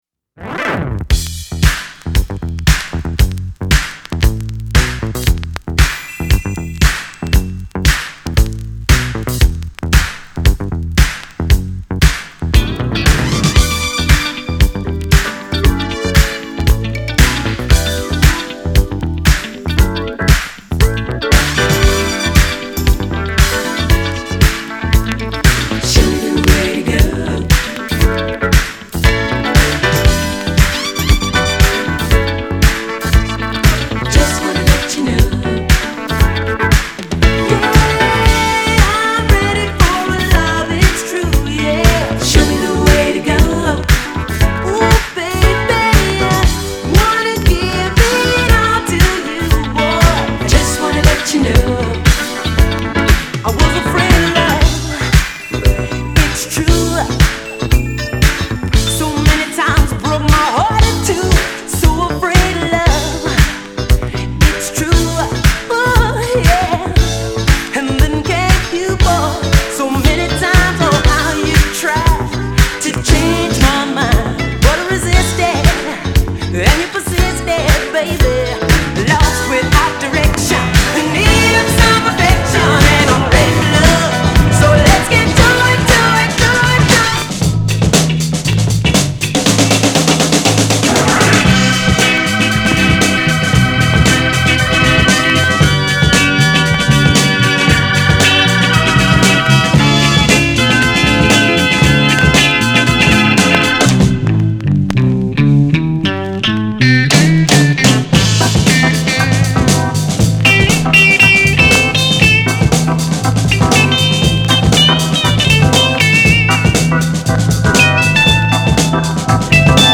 /盤質/両面やや傷あり/US PRESS